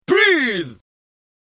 Human